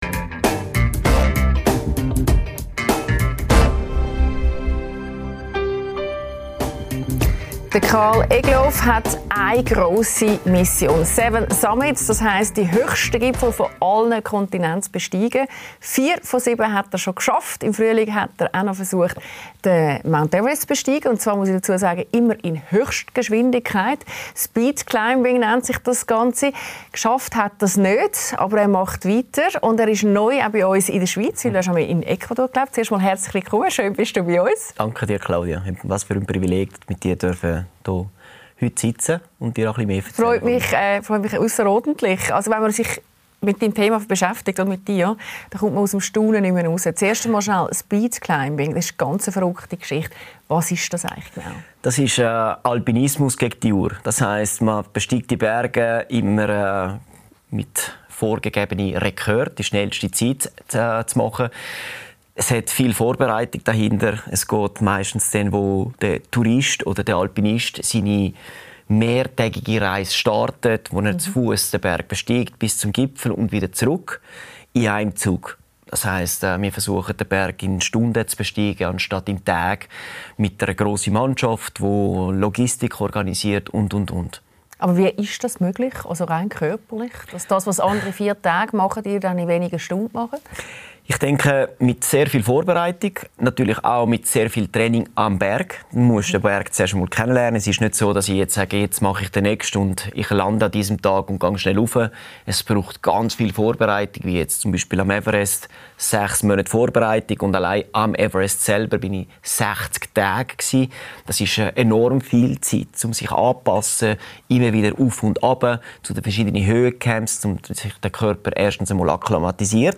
Extrembergsteiger mit bewegter Geschichte: Karl Egloff spricht mit Moderatorin Claudia Lässer über seine Grenzerfahrungen als Alpinist, seine Zeit in Ecuador – und warum er mit seiner Familie zurück in die Schweiz gekehrt ist.